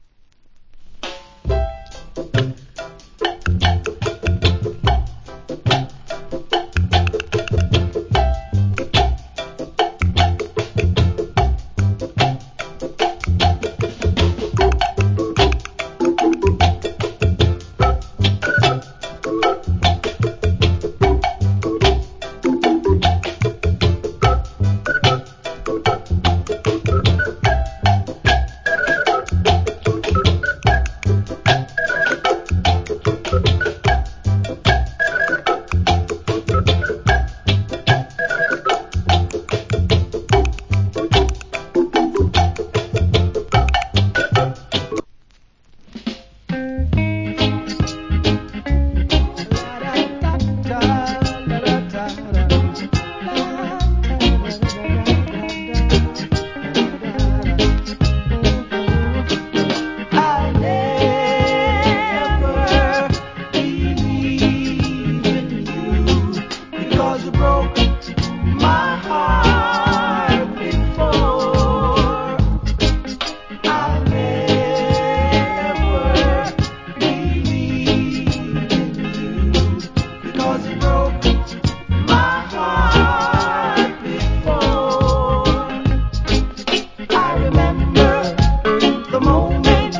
Cool Reggae Inst.